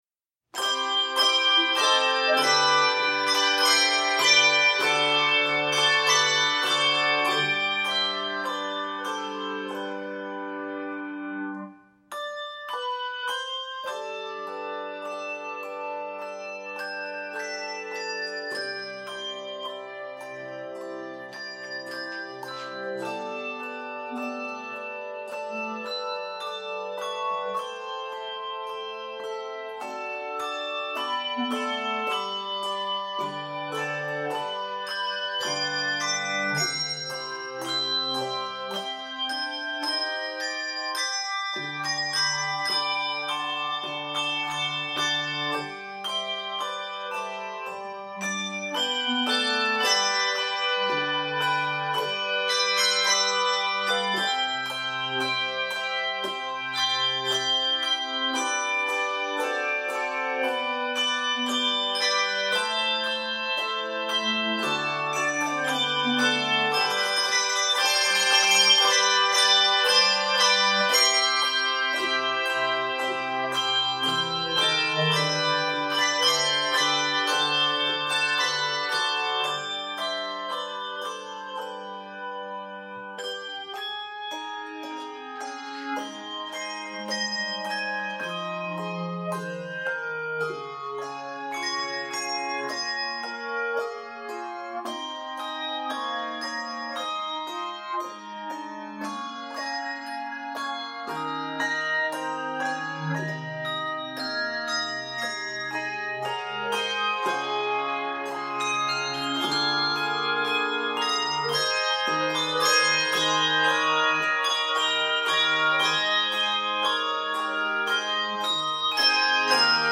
classical gospel hymn